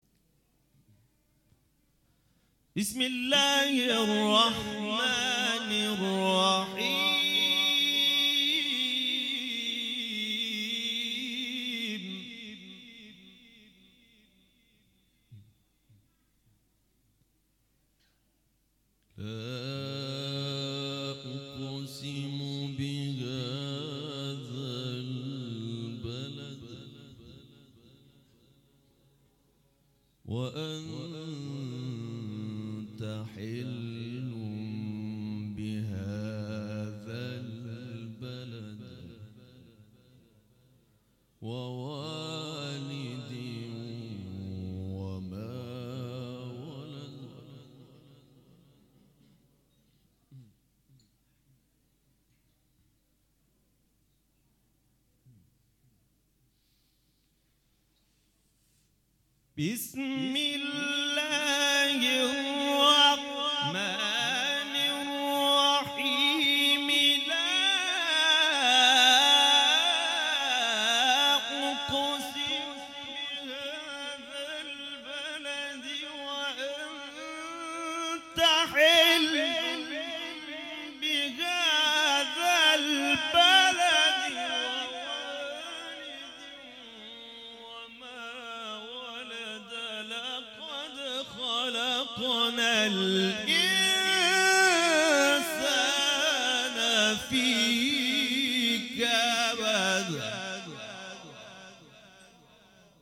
نغمات صوتی از قاریان ممتاز کشور
گروه شبکه اجتماعی: فرازهای صوتی از قاریان ممتاز و تعدادی از قاریان بین‌المللی کشورمان را می‌شنوید.